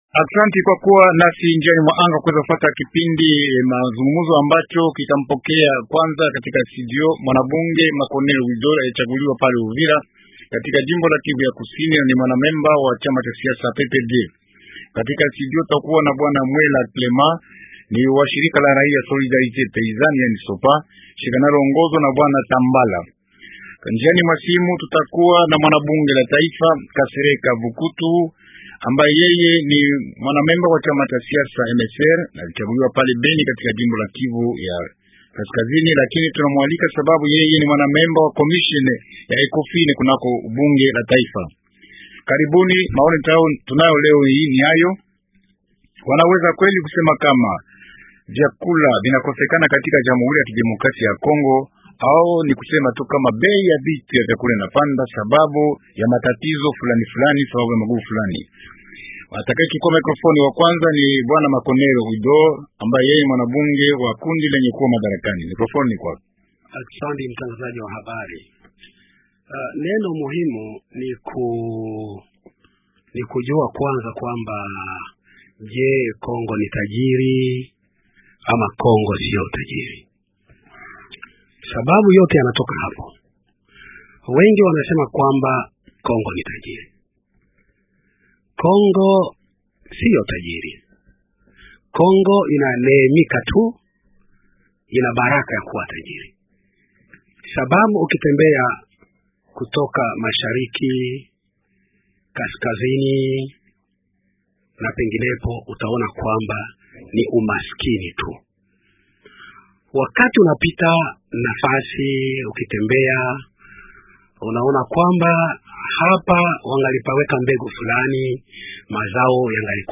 -Wildor Makonero , mwanabunge la taifa, ni pia mwanamemba wa chama cha siasa PPRD,